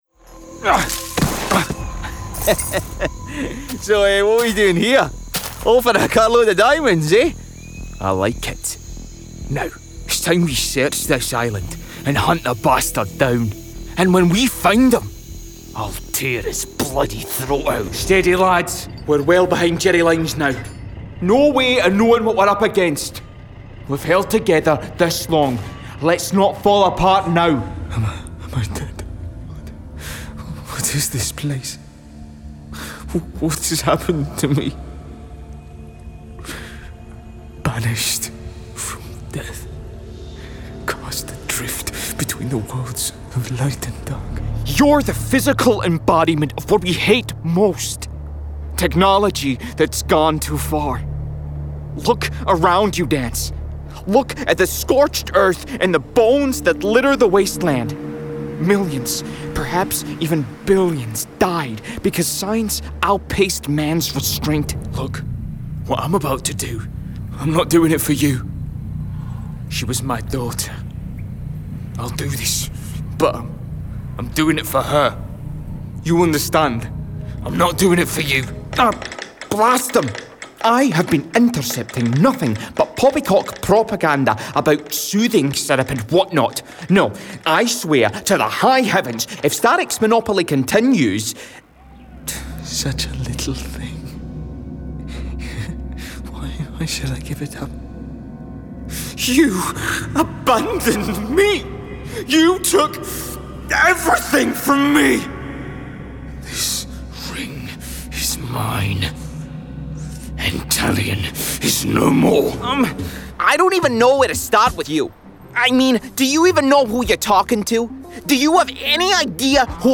Video Game Reel
• Native Accent: Glasgow
• Home Studio